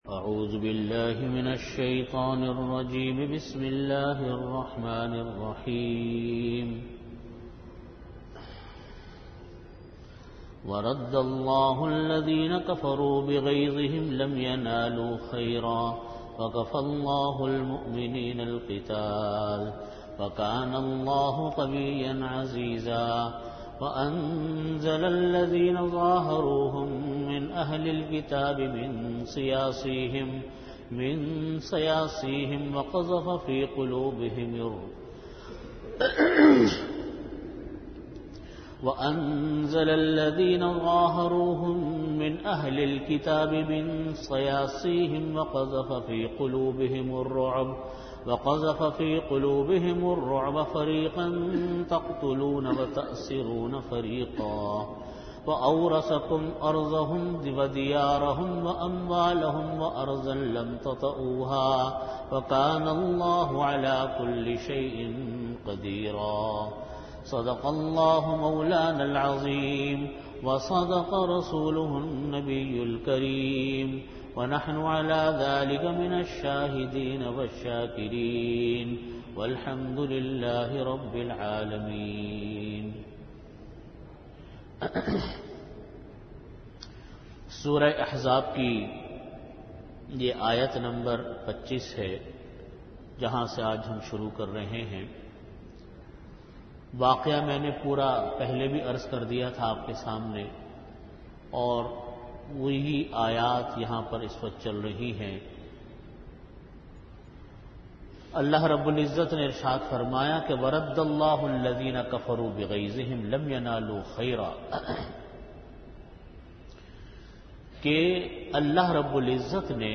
Audio Category: Tafseer
Venue: Jamia Masjid Bait-ul-Mukkaram, Karachi